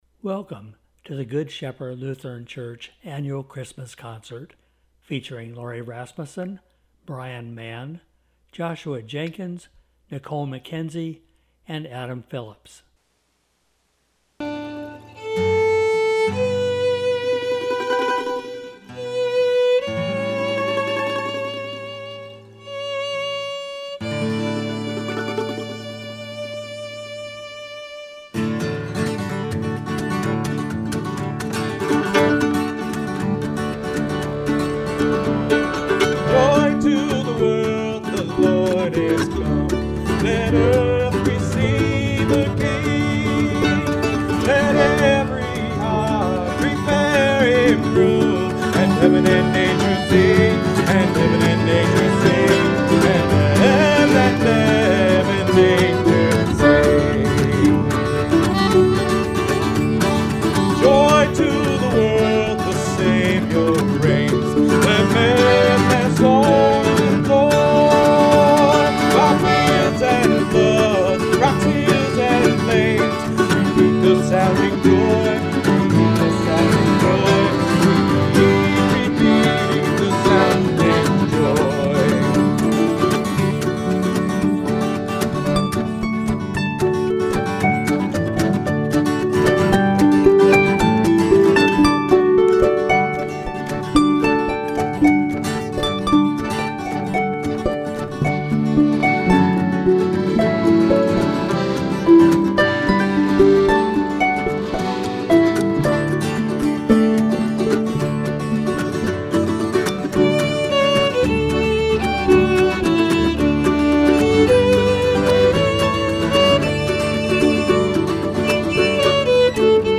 Good Shepherd Lutheran Church and Preschool, Goleta, CA - GSLC 2022 Christmas Concert
2022_GSLC_Christmas_Concert.mp3